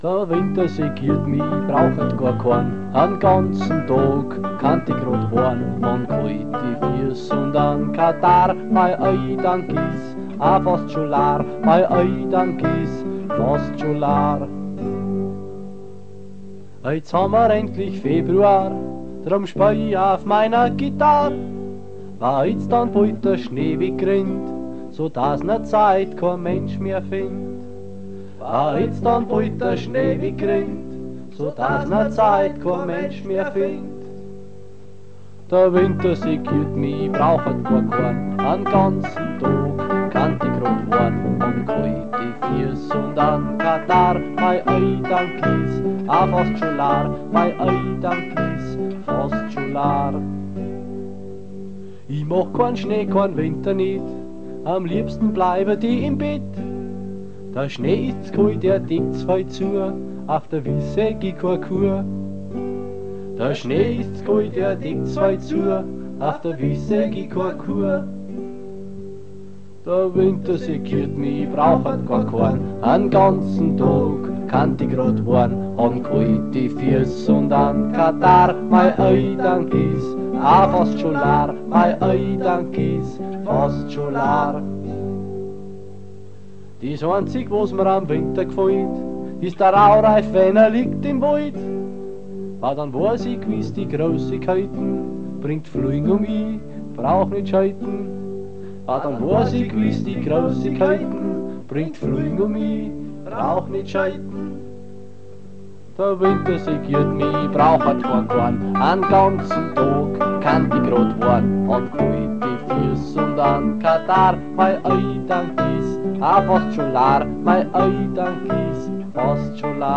Vozwickte Mundartsongs